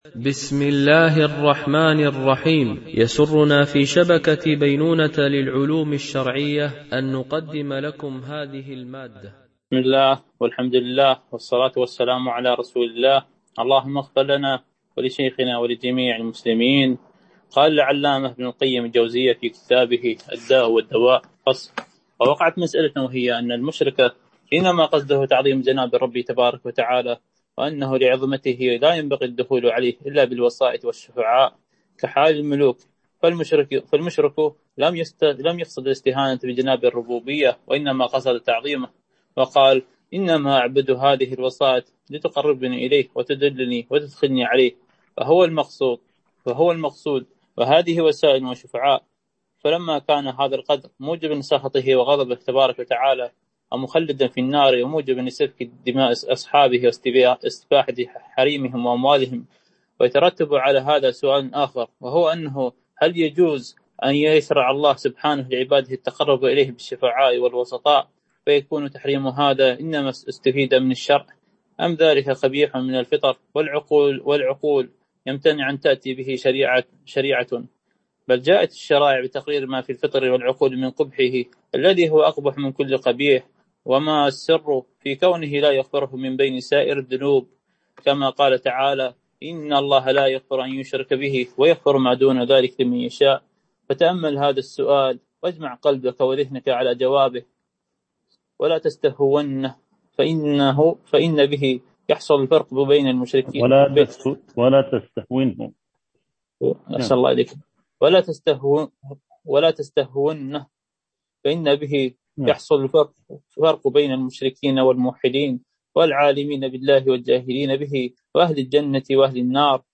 شرح كتاب الداء والدواء ـ الدرس 32